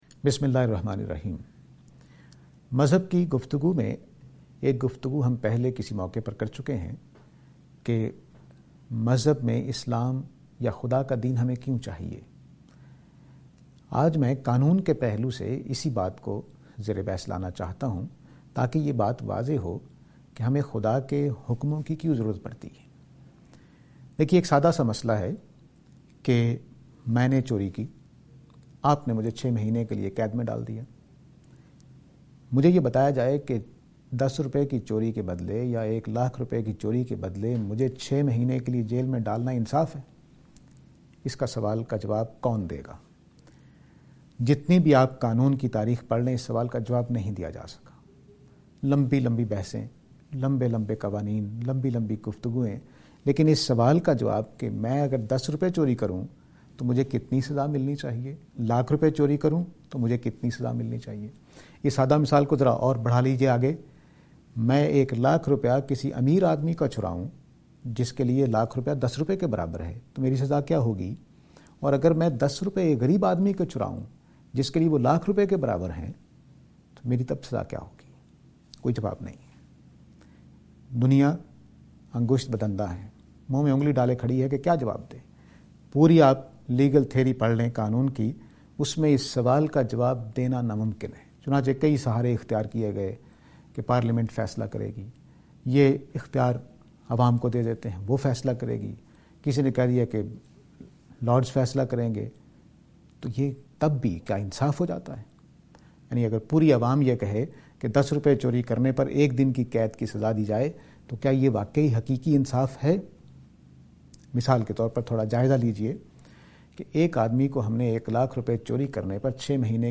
This lecture is and attempt to answer the question "God and Law".